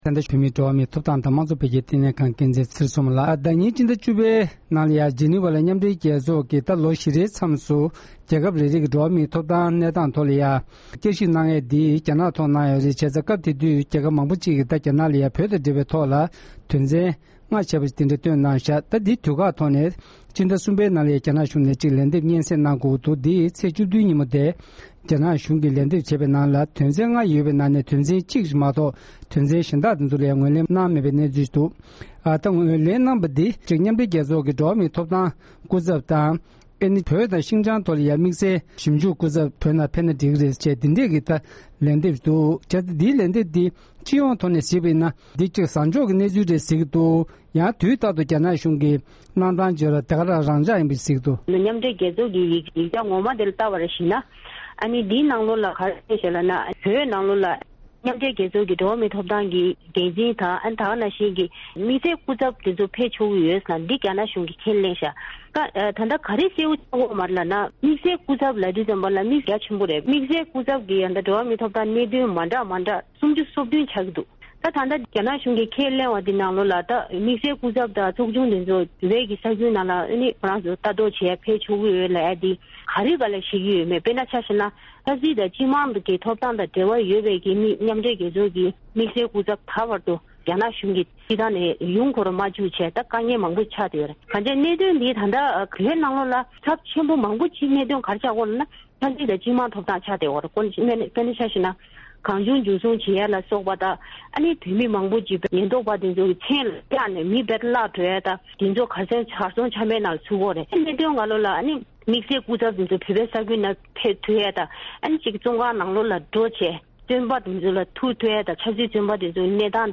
དཔྱད་གཞིའི་དགོངས་ཚུལ་བཅར་འདྲི་ཞུས་པར་གསན་རོགས༎